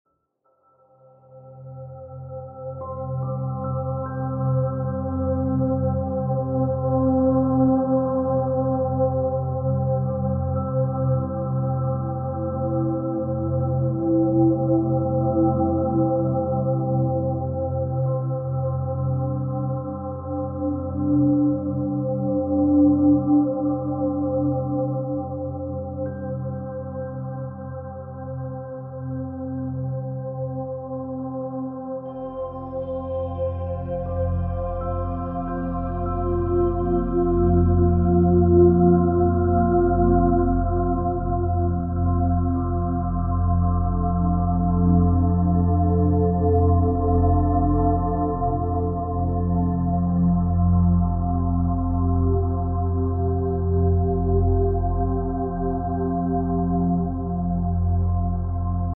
8D Audio Music To Brain Sound Effects Free Download